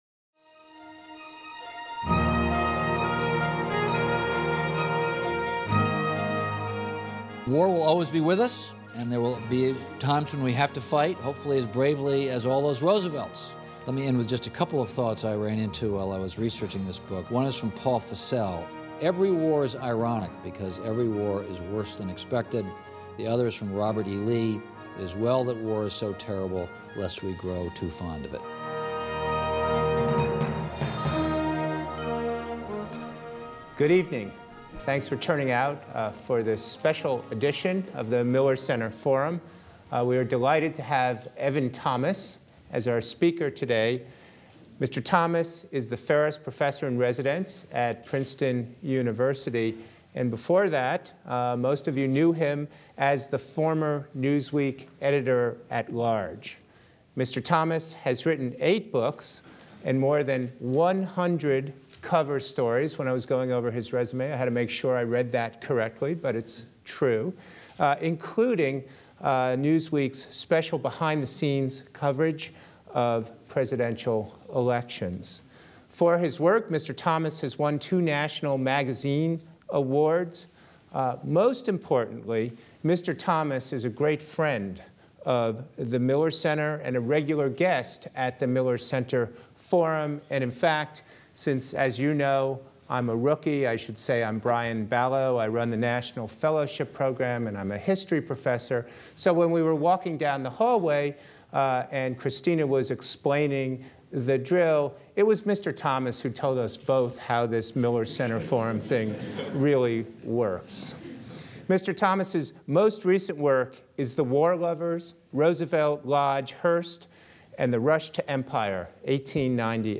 A book signing will follow his Forum.